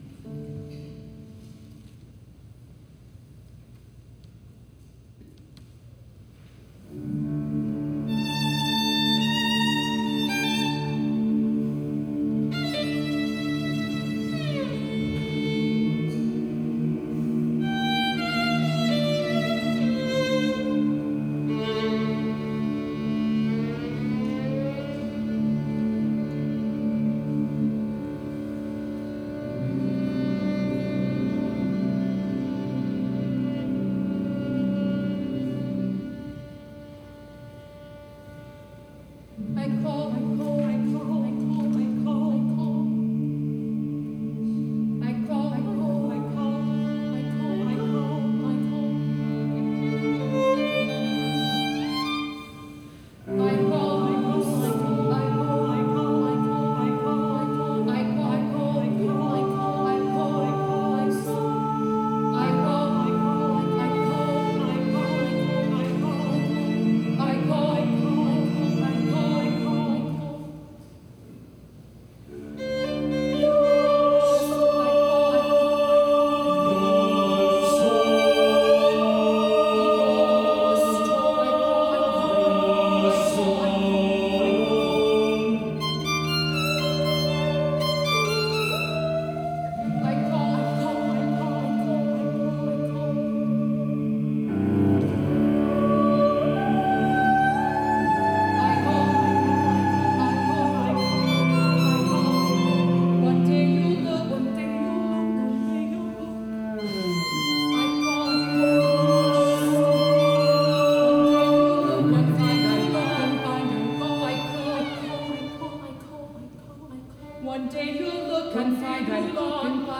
SATB, violin, cello